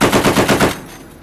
.50 cal bursts
Isolated from a test firing range video.
Burst lengths vary a little, it will give your unit a little variety so as not to sound repetitive.